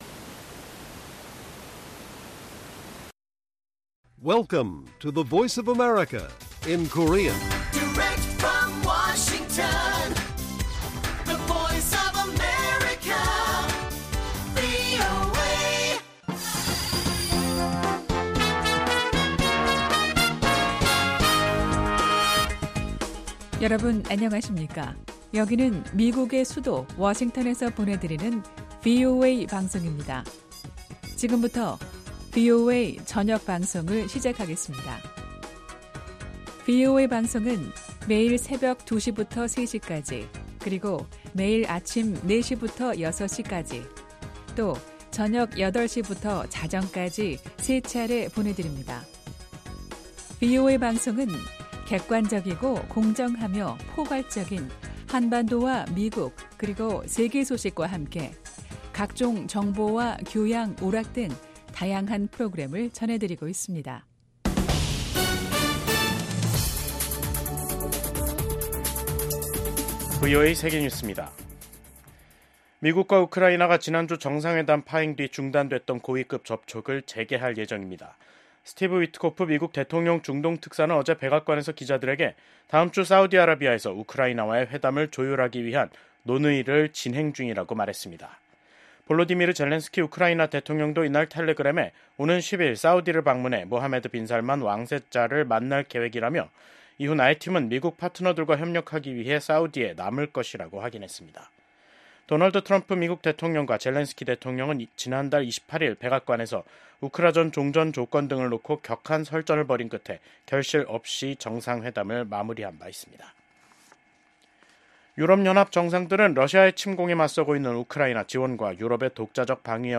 VOA 한국어 간판 뉴스 프로그램 '뉴스 투데이', 2025년 3월 7일 1부 방송입니다. 미국 도널드 트럼프 대통령의 핵심 측근이 북러 밀착을 지적하며 한반도 전쟁 시 러시아군이 참전할 수 있다고 말했습니다. 미국 재무부 부장관 지명자는 트럼프 행정부의 관세 정책이 상호주의에 중점을 두고 있다고 밝혔습니다. 미국 전문가들은 도널드 트럼프 대통령이 의회 연설에서 미국의 조선업 부활을 천명한 것이 한국에 기회가 될 수 있다고 진단했습니다.